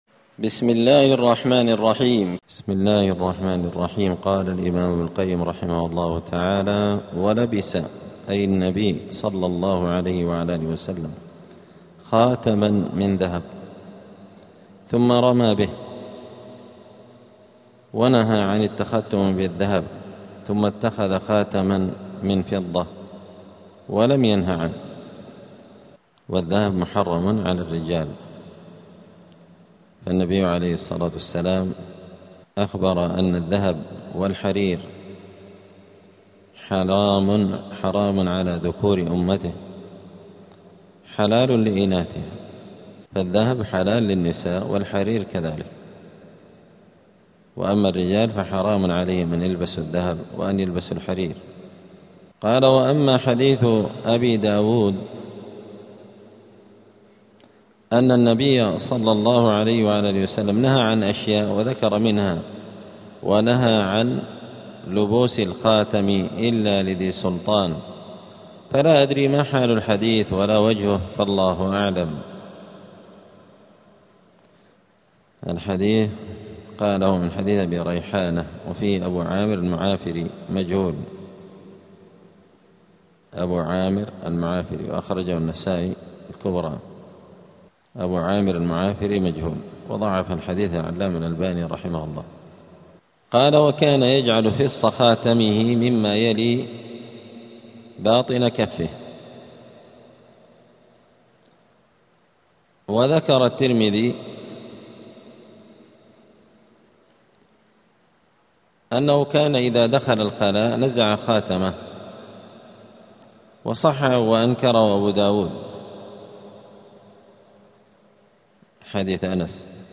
25الدرس-الخامس-والعشرون-من-كتاب-زاد-المعاد.mp3